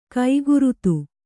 ♪ kaigurutu